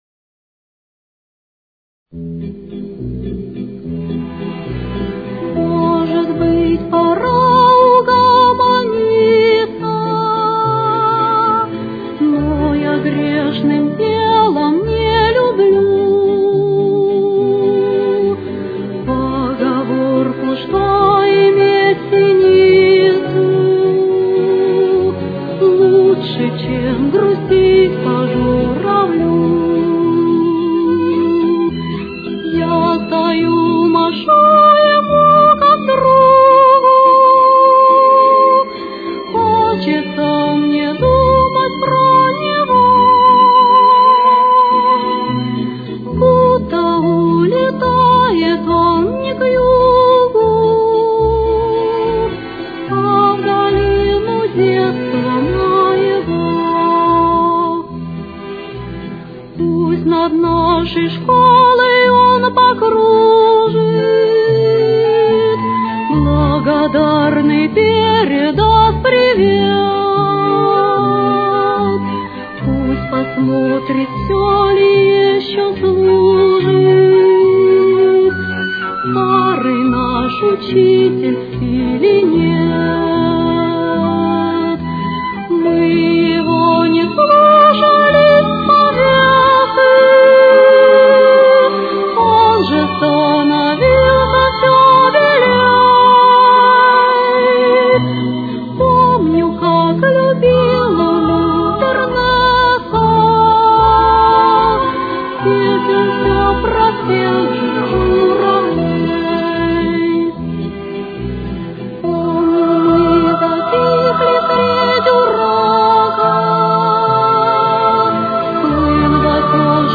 Темп: 226.